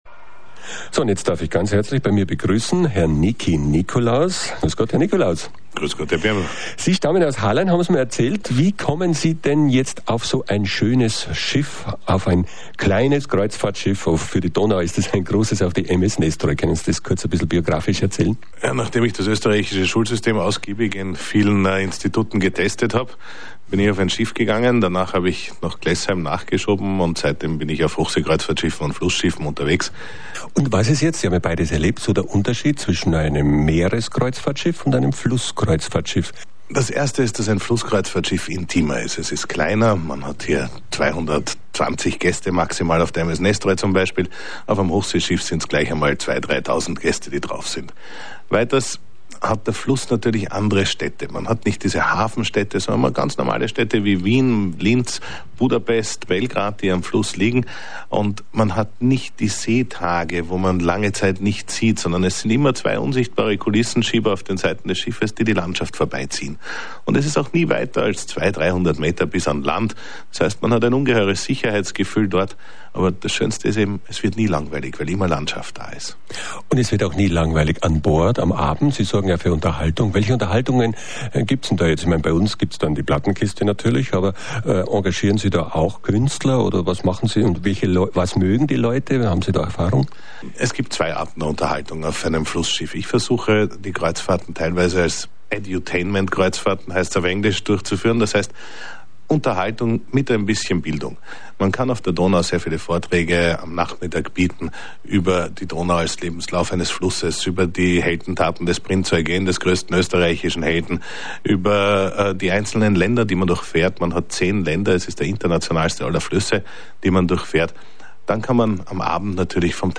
Interview zur Kreuzfahrt 2012